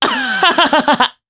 Kasel-Vox_Happy3_jp.wav